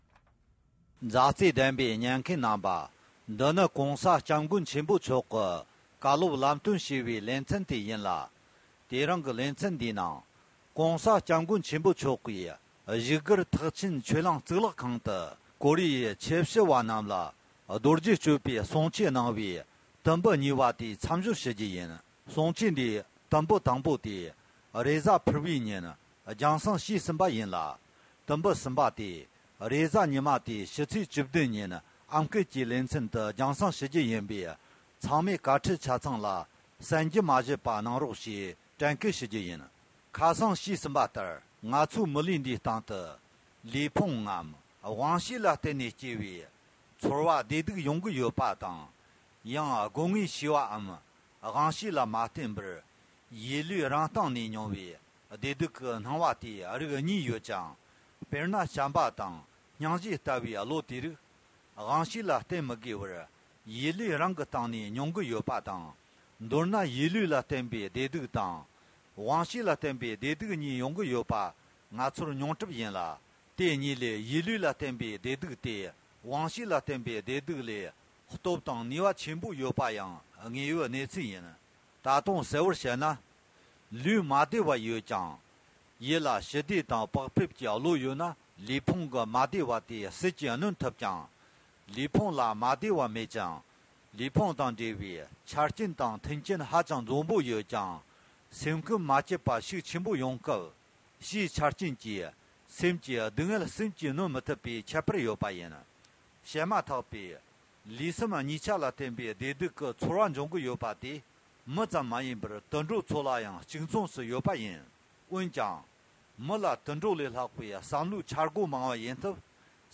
༸གོང་ས་མཆོག་ནས་ཀོ་རི་ཡའི་དད་ལྡན་ཆོས་ཞུ་བ་རྣམས་ལ་བཞུགས་སྒར་ཐེག་ཆེན་ཆོས་གླིང་གཙུག་ལག་ཁང་དུ་རྡོ་རྗེ་གཅོད་པའི་བཀའ་ཆོས་གནང་བའི་དུམ་བུ་གཉིས་པ།